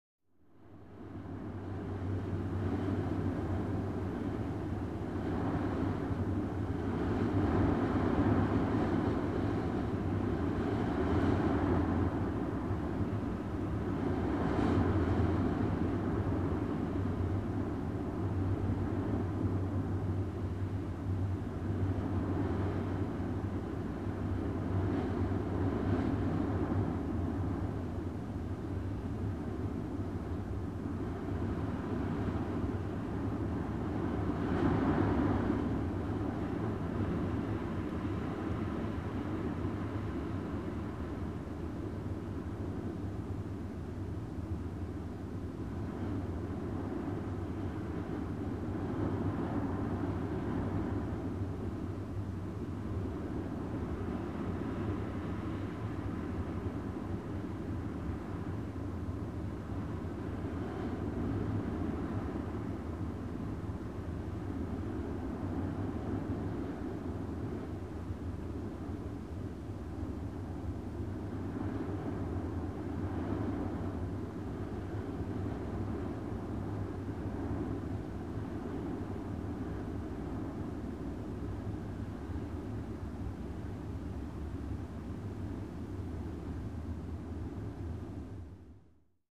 Шепот легкого бриза